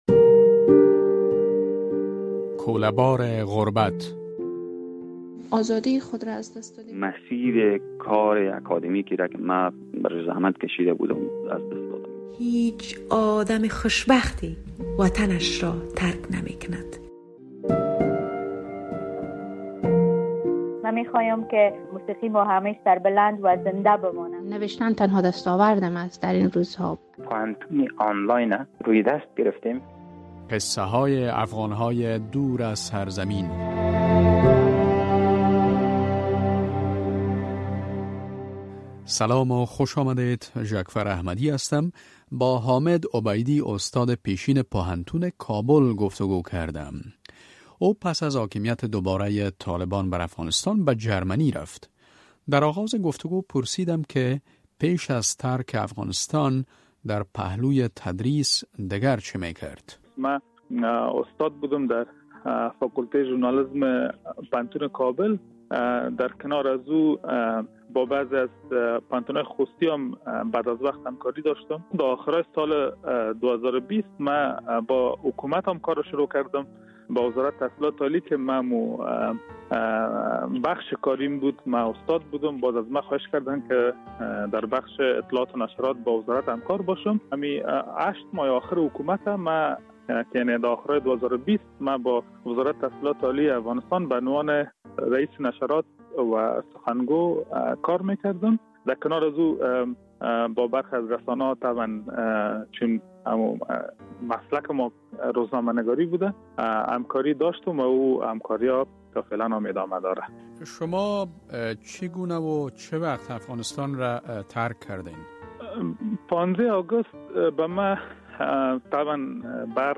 رادیو آزادی سلسله ای از گفت و گو های جالب با آن عده از شهروندان افغانستان را آغاز کرده است که پس از حاکمیت دوبارۀ طالبان بر افغانستان، مجبور به ترک کشور شده اند.